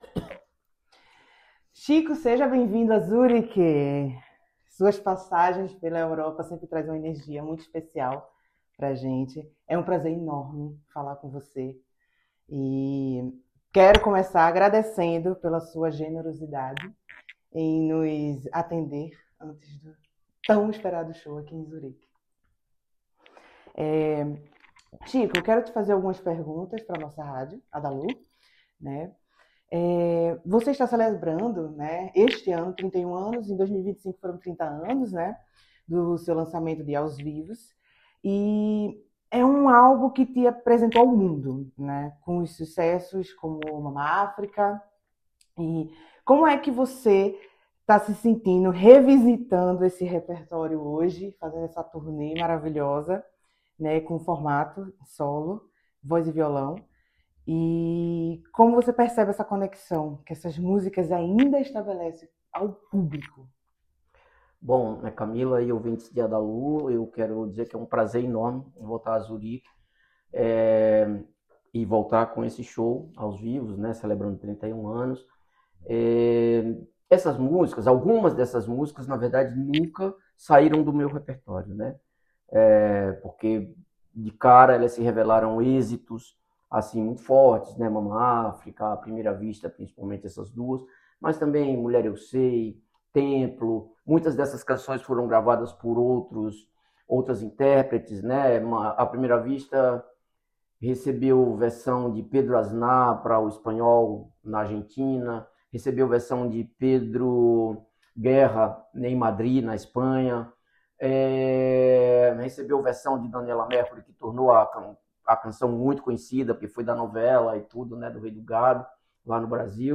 Im Gespräch mit Künstler:innen
Entrevista-Chico-Cezar.mp3